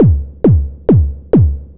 Kick 135-BPM.wav